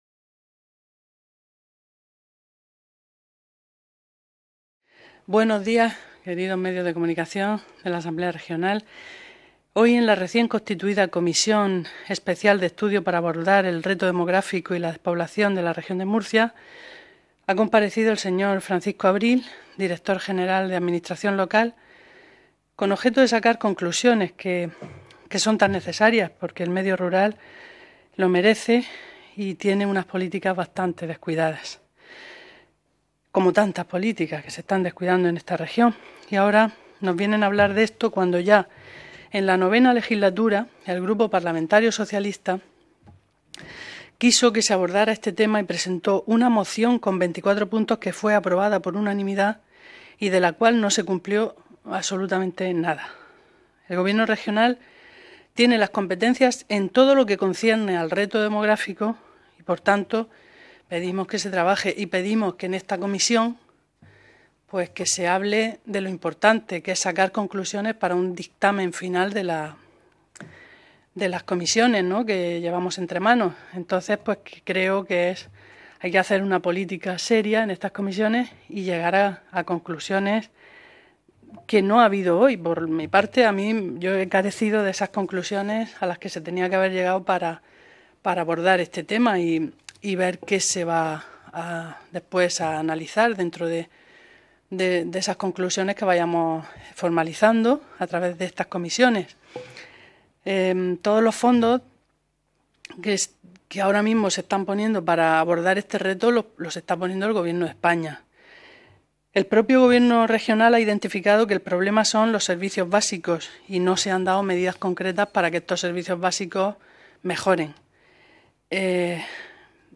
Ruedas de prensa posteriores a la Comisión Especial de Estudio para abordar el Reto Demográfico y la Despoblación en la Región de Murcia
• Francisco Abril Ruiz, director general de Administración Local de la Consejería de Presidencia, Portavocía y Acción Exterior
• Grupo Parlamentario Vox
• Grupo Parlamentario Popular
• Grupo Parlamentario Socialista